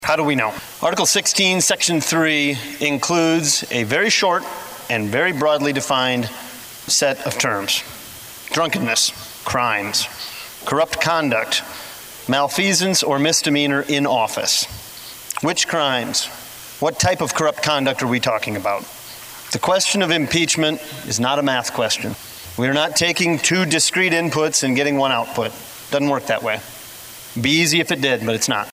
Mortenson, who is an attorney, said the question that needed to be answered was “is the Attorney General’s conduct impeachable?”